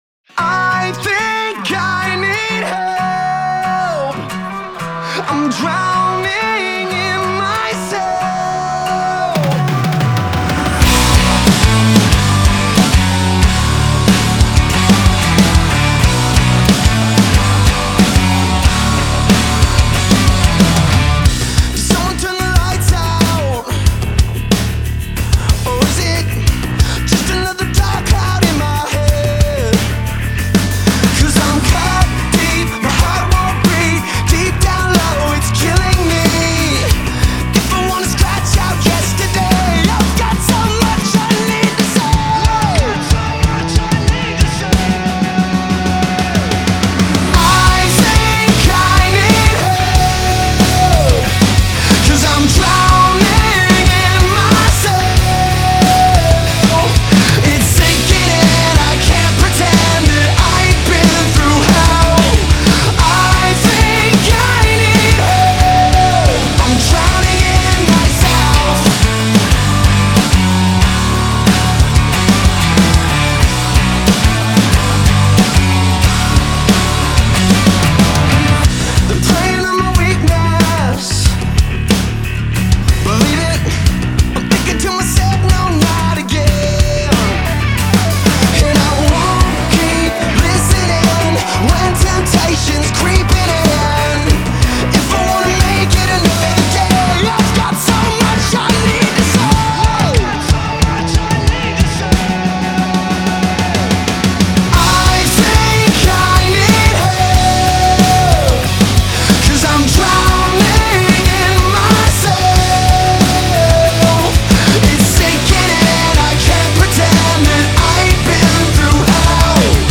Genre : Alternative Rock